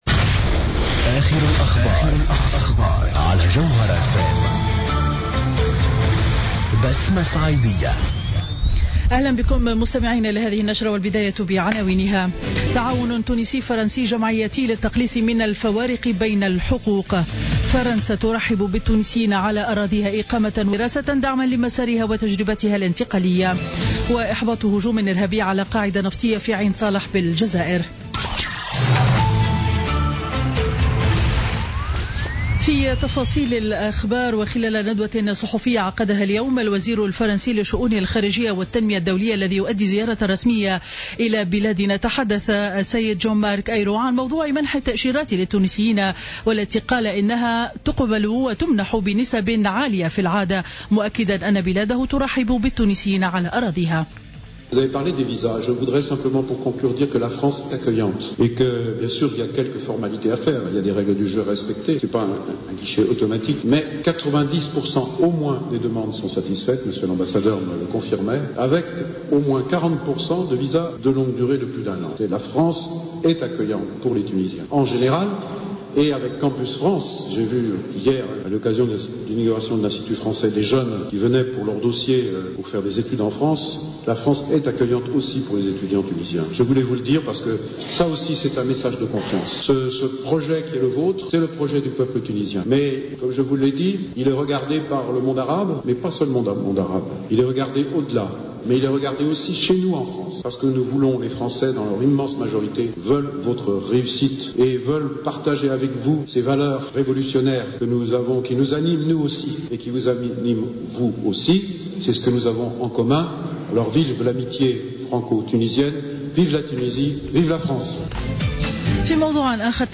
نشرة أخبار منتصف النهار ليوم الجمعة 18 مارس 2016